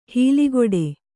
♪ hīligoḍe